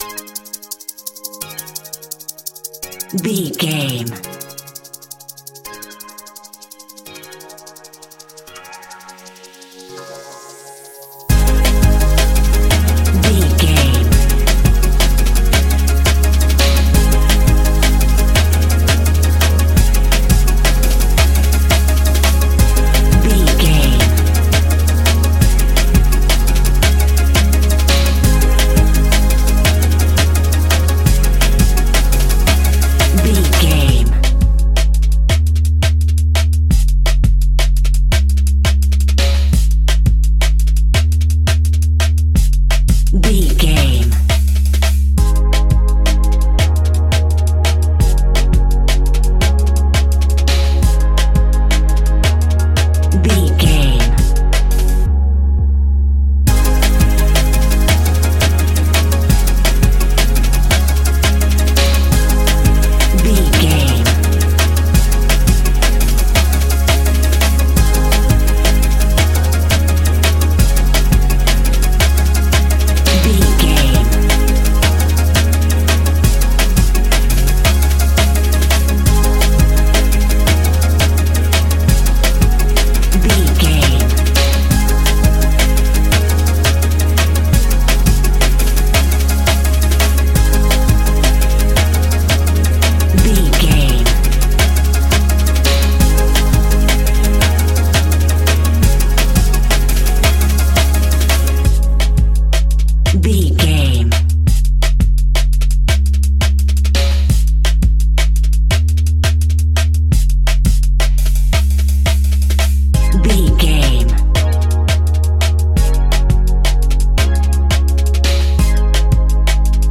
Ionian/Major
C♭
electronic
techno
trance
synthesizer
synthwave
instrumentals